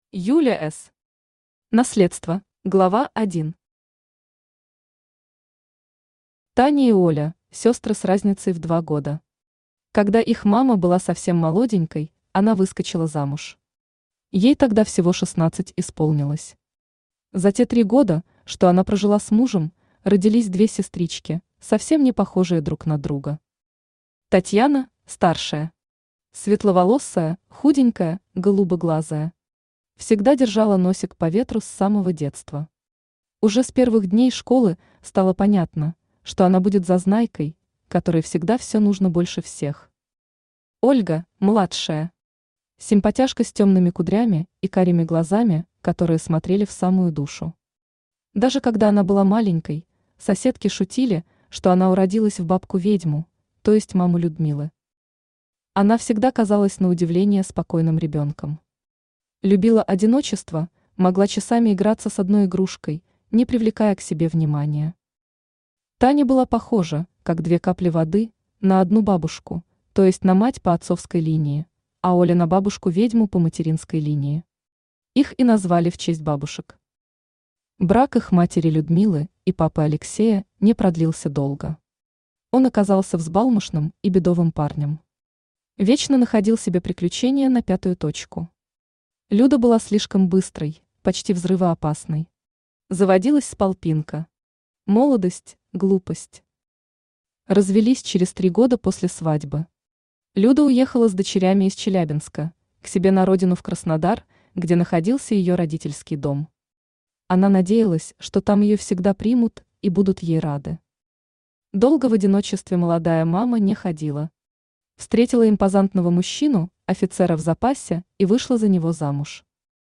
Аудиокнига Наследство